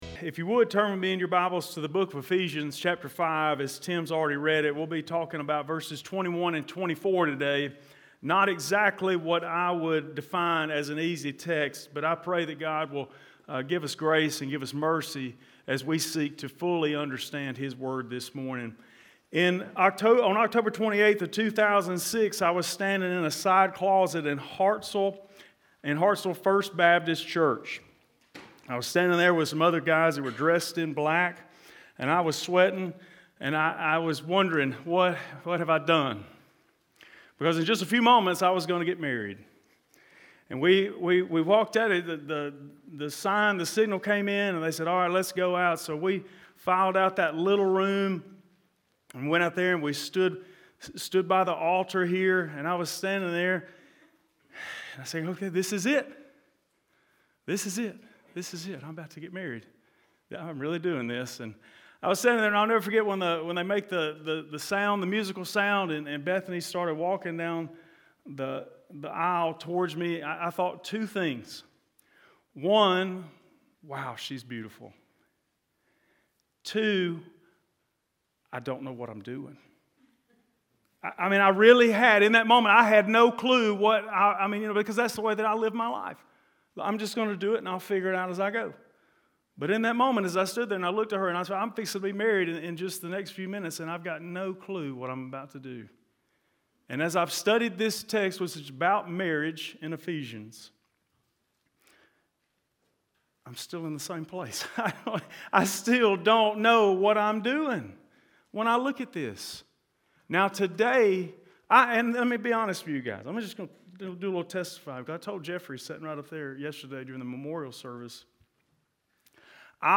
This is a 2-part sermon on the conduct of husbands and wives in a marriage. This sermon is focused on the wives and next week the spotlight will shift to the husband.